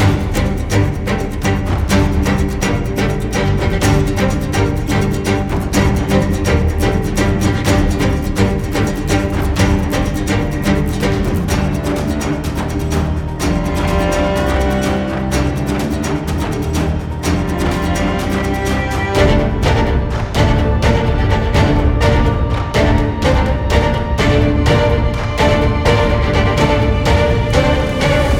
• Качество: 320, Stereo
саундтреки
без слов
инструментальные
эпичные
музыка из игр
пиратские мотивы